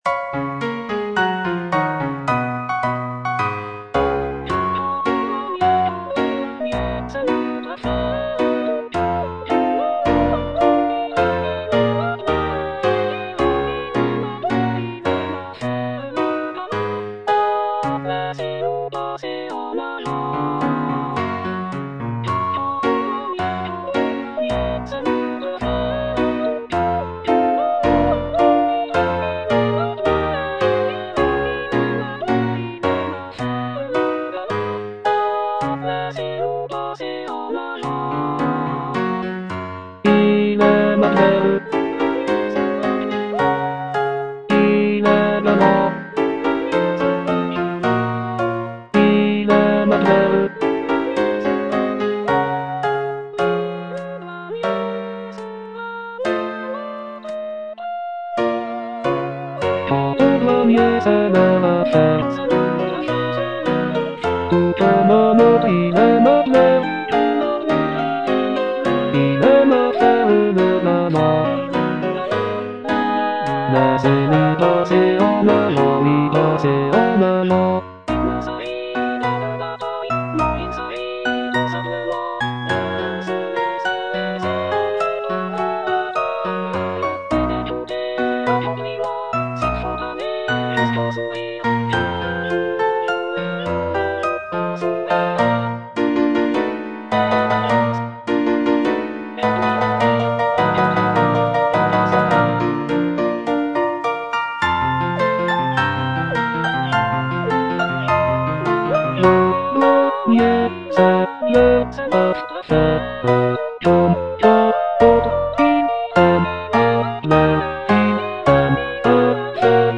G. BIZET - CHOIRS FROM "CARMEN" Quant au douanier (bass I) (Voice with metronome) Ads stop: auto-stop